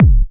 Kick OS 06.wav